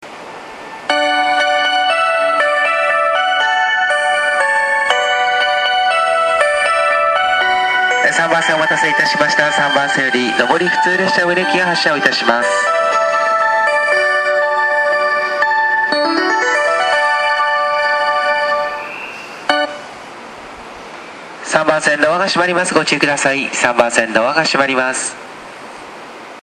３番線常磐線
発車メロディー1.1コーラスです。